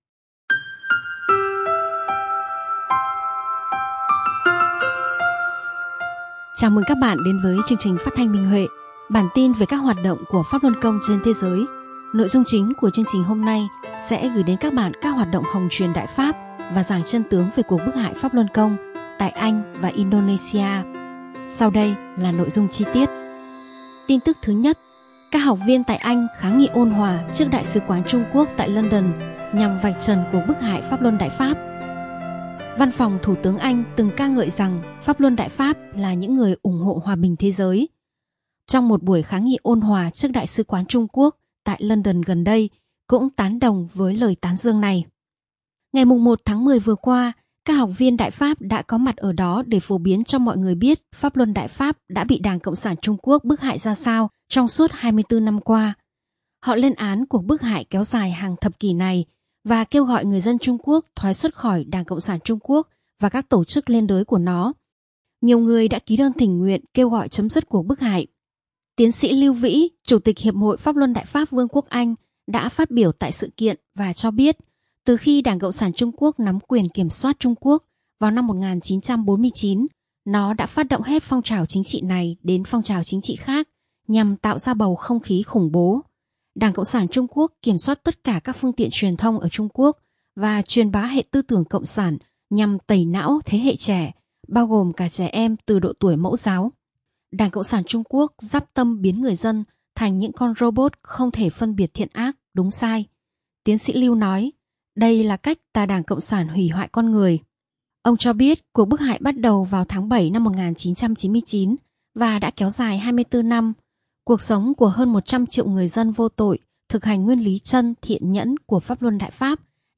Chương trình phát thanh số 77: Tin tức Pháp Luân Đại Pháp trên thế giới – Ngày 13/10/2023